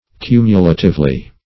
Meaning of cumulatively. cumulatively synonyms, pronunciation, spelling and more from Free Dictionary.
cumulatively.mp3